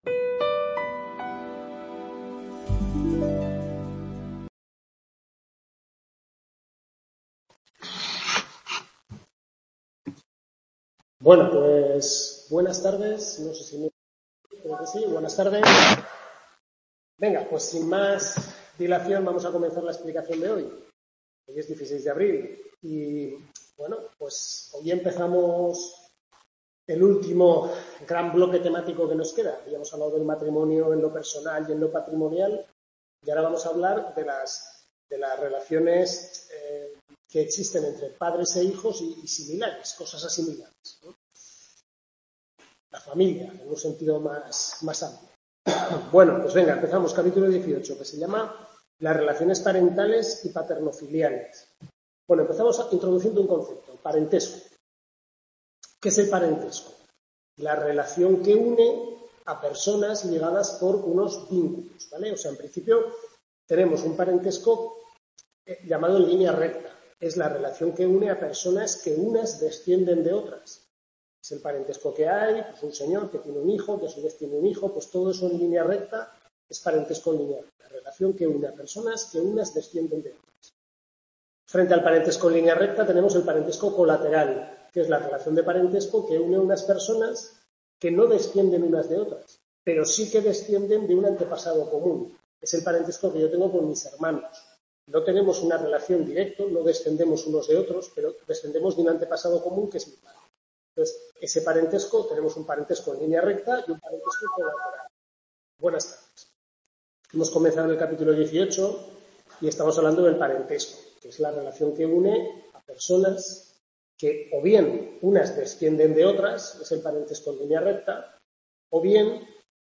Tutoría 4/5 Derecho de Familia